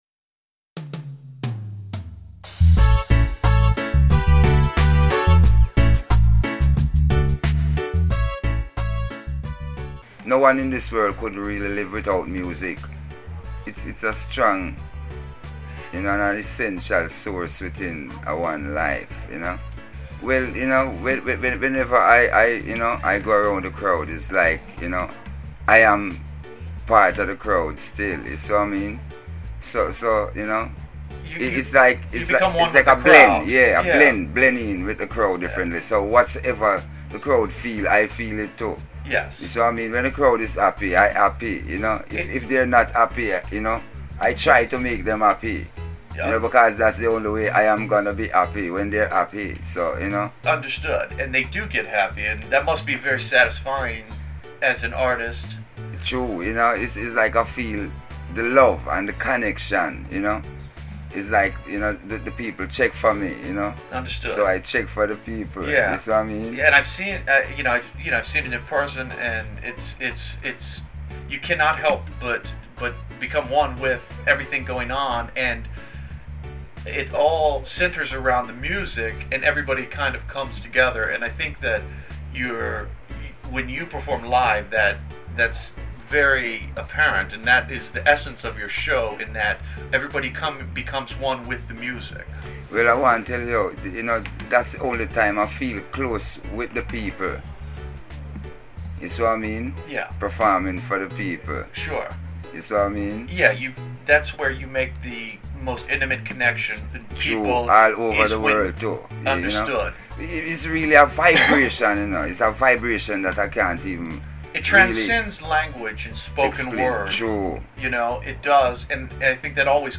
REGGAE MUSIC